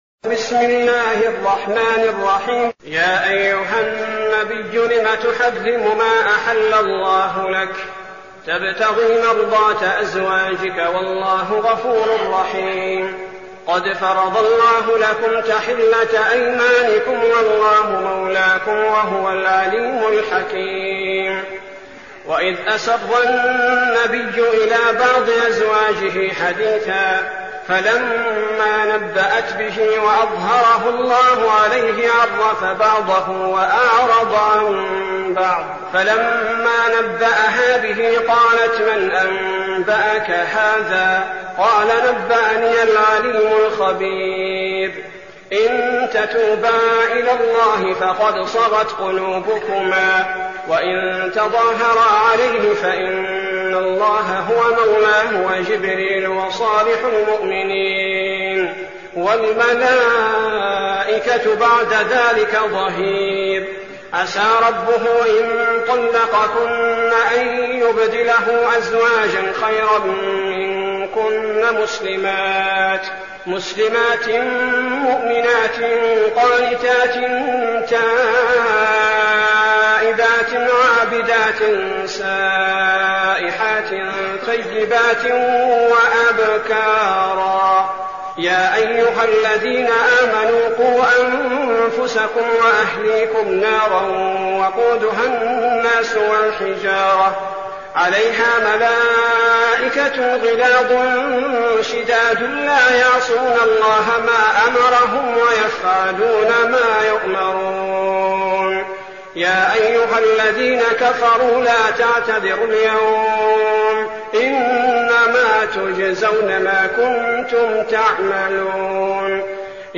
المكان: المسجد النبوي الشيخ: فضيلة الشيخ عبدالباري الثبيتي فضيلة الشيخ عبدالباري الثبيتي التحريم